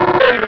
Cri de Kraknoix dans Pokémon Rubis et Saphir.